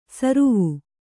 ♪ saruvu